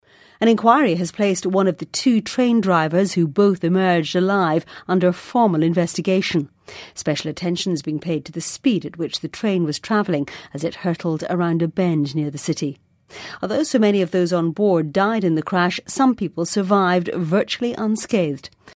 【英音模仿秀】西班牙列车脱轨 听力文件下载—在线英语听力室